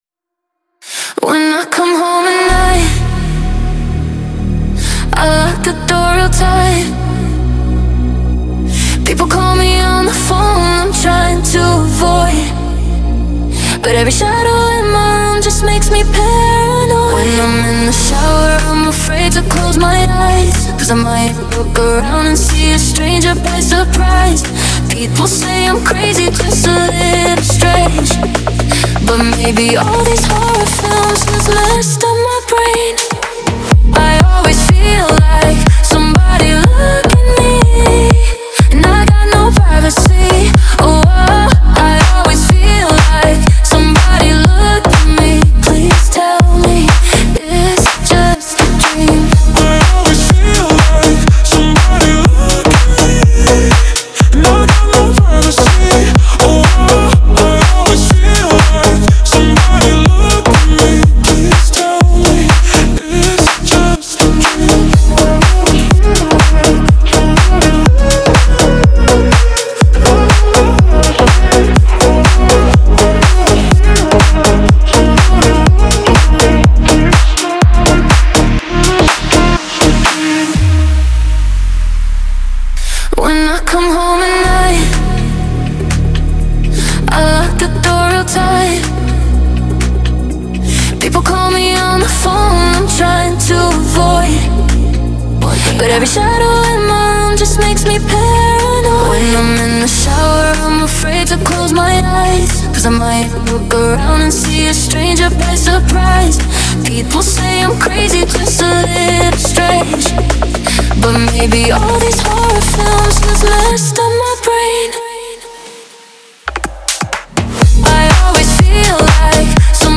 танцевальная музыка
диско